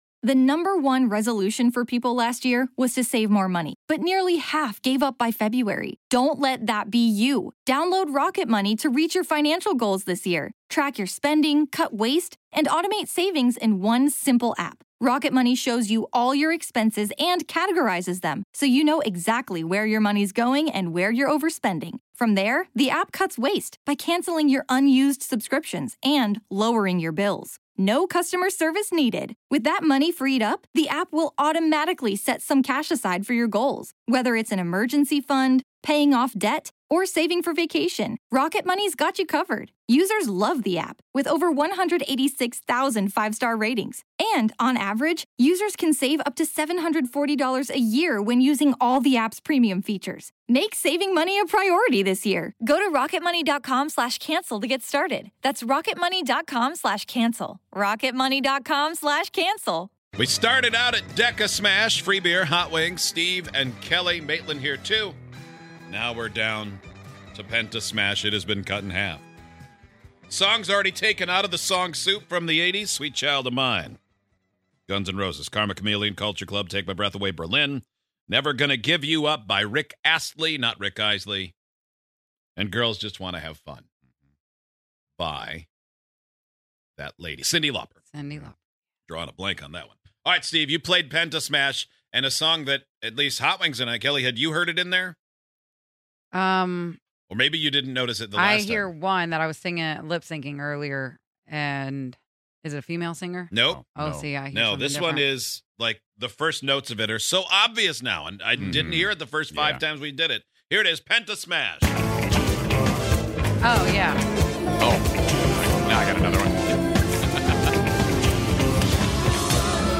We guess the songs one by one until they've all been guessed.